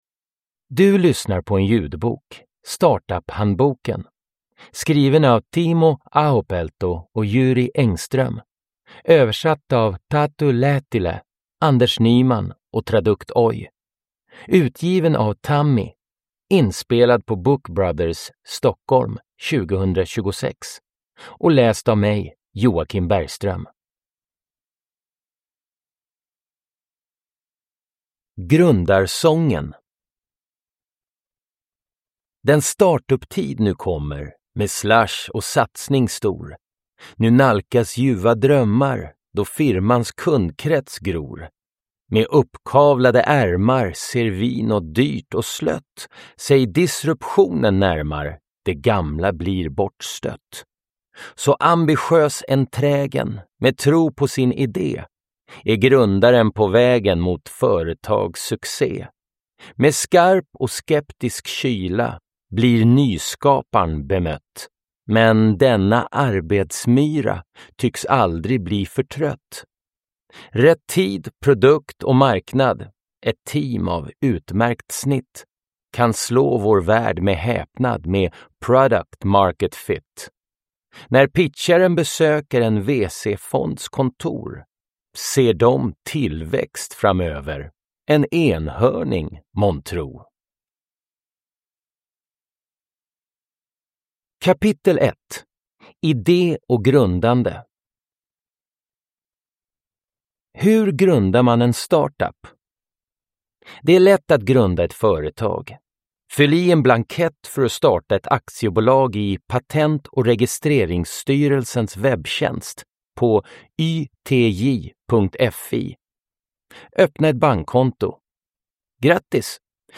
Startuphandboken – Ljudbok